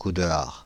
French pronunciation of « Coudehard »